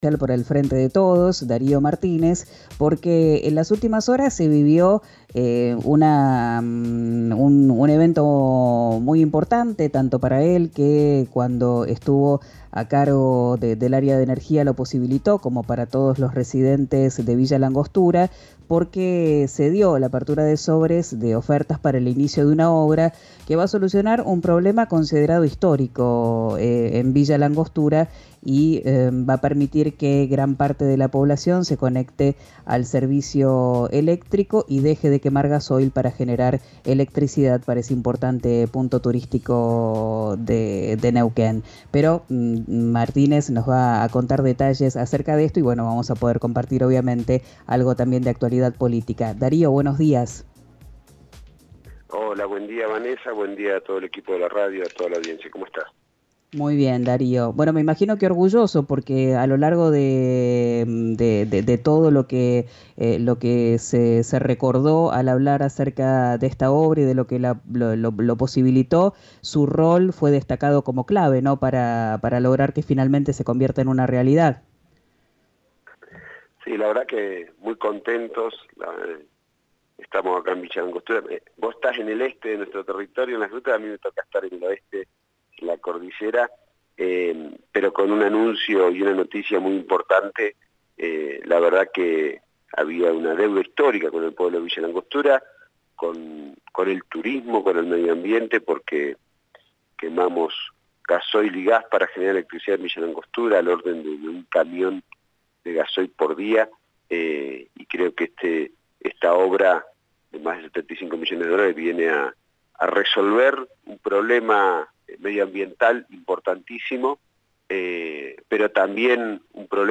En diálogo con «Quien dijo verano», por RÍO NEGRO RADIO, aseguró que la vicepresidenta es la figura «más importante» del Frente de Todos, «el mejor cuadro político» que pueden aportar al electorado.